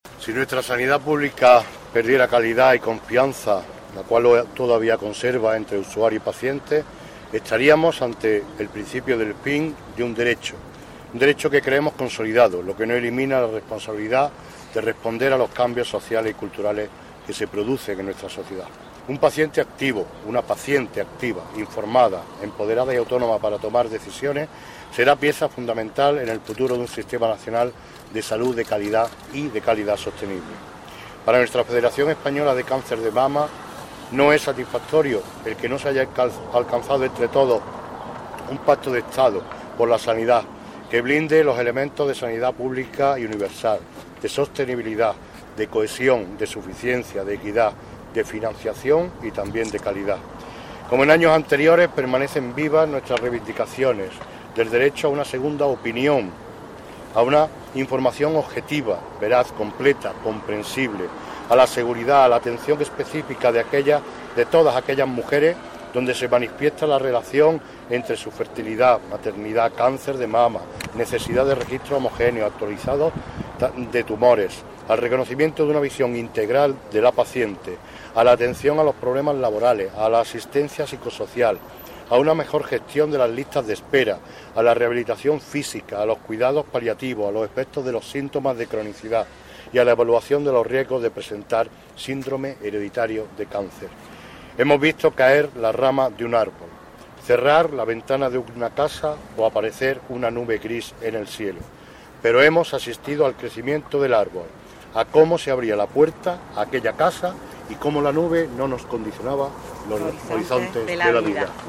Audios y fotos relativos a la lectura del manifiesto con motivo de la conmemoración del Día Mundial contra el Cáncer de Mama que ha tenido lugar en la tarde de hoy lunes en la plaza de Castilla.
Cortes de voz